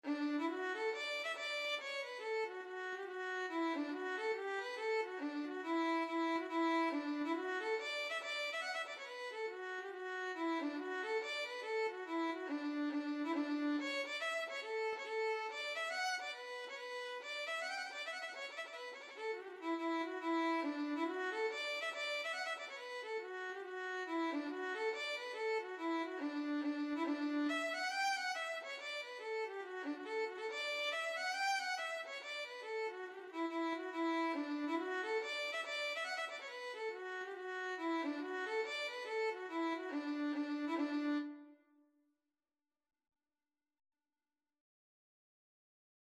Violin version
D major (Sounding Pitch) (View more D major Music for Violin )
4/4 (View more 4/4 Music)
D5-G6
Violin  (View more Intermediate Violin Music)
Traditional (View more Traditional Violin Music)
Irish